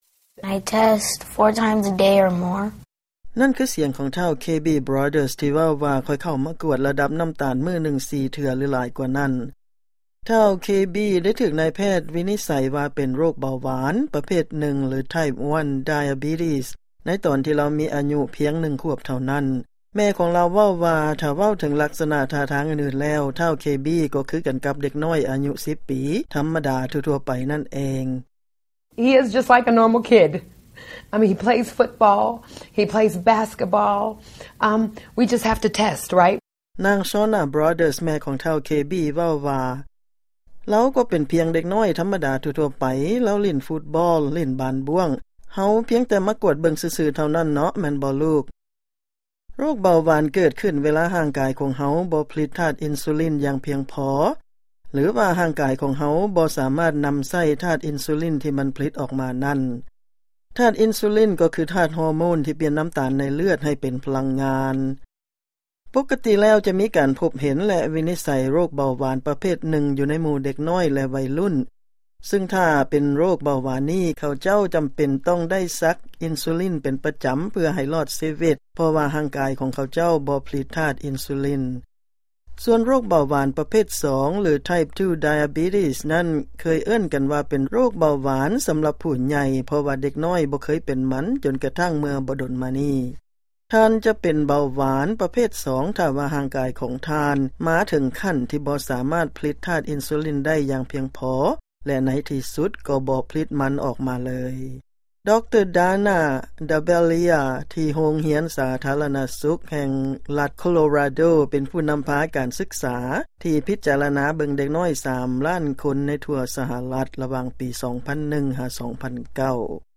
ຟັງລາຍງານເລື້ອງ ເດັກນ້ອຍເປັນໂຣກເບົາຫວານ ຫລາຍຂຶ້ນຢູ່ໃນທົ່ວໂລກ